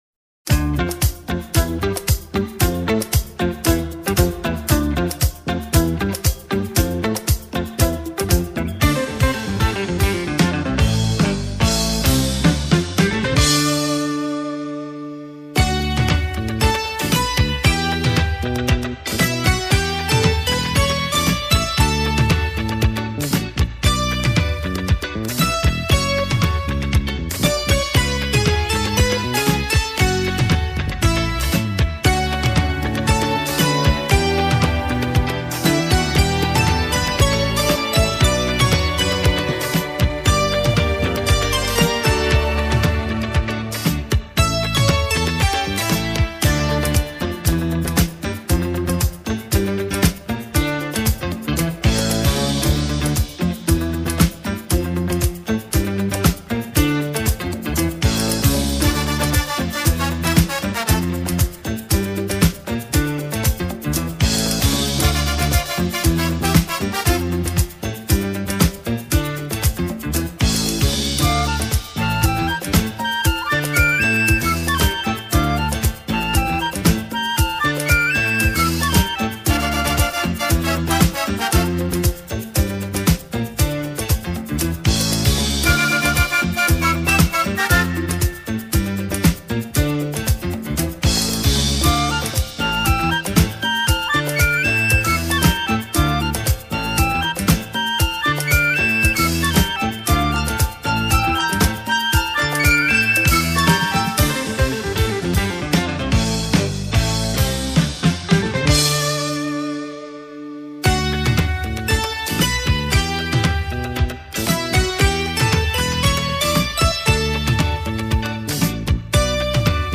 Genre:Easy Listening,Instrumental